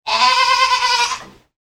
دانلود صدای بز 6 از ساعد نیوز با لینک مستقیم و کیفیت بالا
جلوه های صوتی